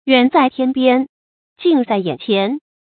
远在天边，近在眼前 yuǎn zài tiān biān，jìn zài yǎn qián 成语解释 形容寻找的人或物就在面前。